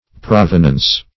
Provenance \Prov"e*nance\, n. [F., fr. provenir to originate, to